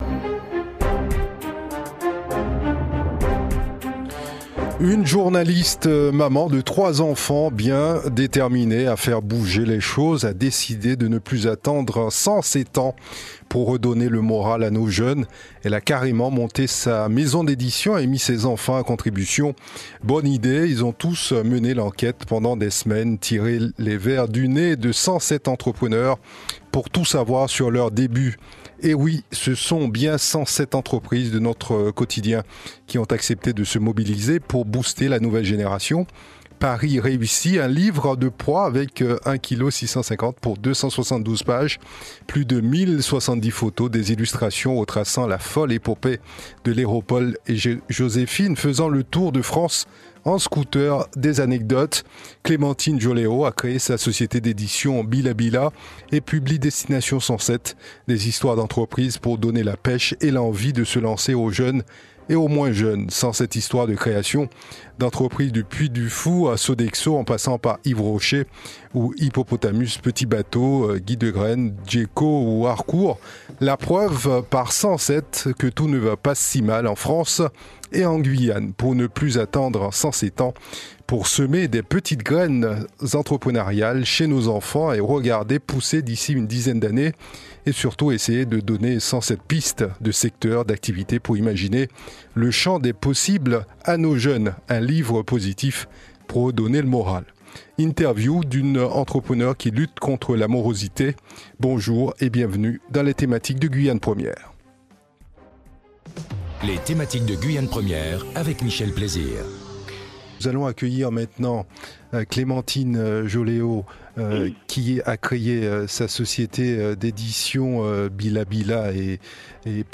Une Interview réalisée à + de 7 089 Km de distance de la Maison du Bila.